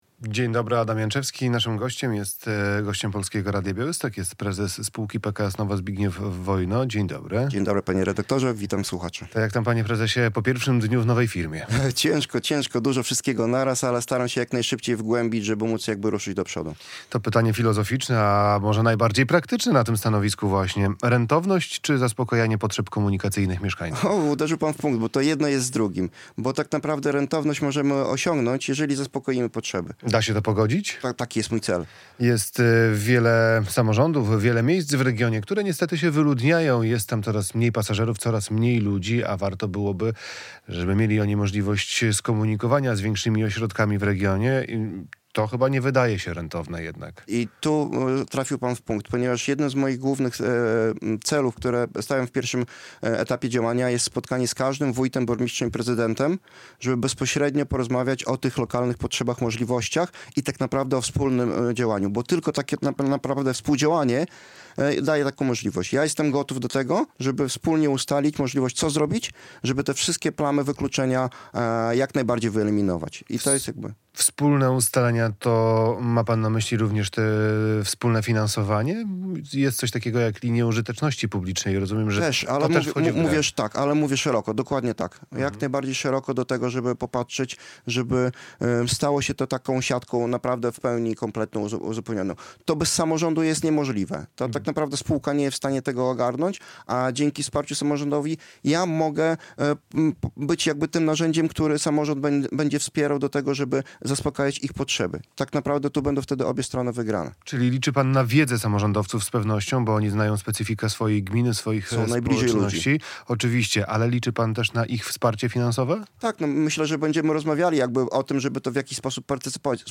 Fragment rozmowy: